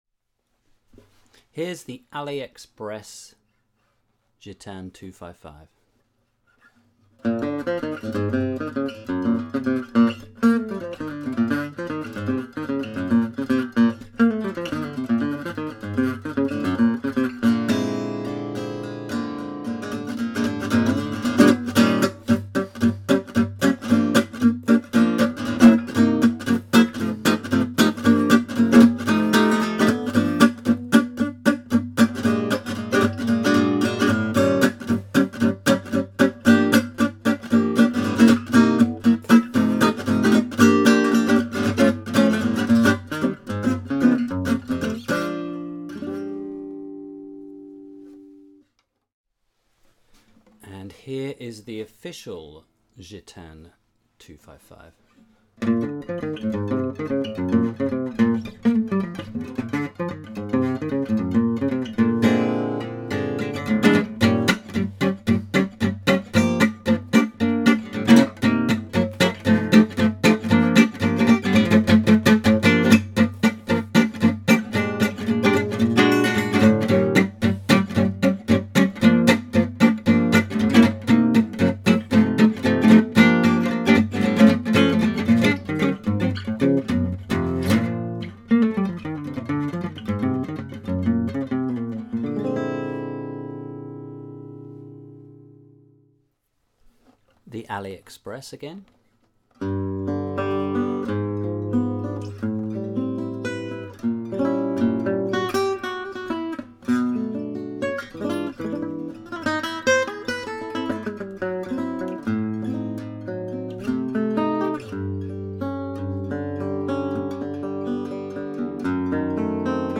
Here is a quick comparison with the Aliexpress Gitane 255 which is still covered with half a ton of lacquer, and an official Gitane 255 I bought second hand on Gumtree a couple of years ago cheap.
No reverb, no effects added (urghh, it needs a smidge of reverb, but I'm trying to illustrate a point!!!). That point is: Before I refinished my official 255, it sounded very similar to the Aliexpress one - bright, brash, a little tinny but ok. As you can hear, that brightness has gone on the refinished one, replaced with a lovely rich deep woody tone - a great example of how a heavy finish holds a guitar's potential and true voice back.
The Aliexpress one sounds quite acceptable but the refinished sounds terrific.
Factory finished one has a lot of overtones, which are actually pleasing. But the refinished one tone is very dry and direct.
Argentine 11s on both. Dugain picks - quite thick at about 4mm I think.